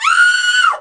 SCREAM8.WAV